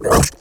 zombieBite.wav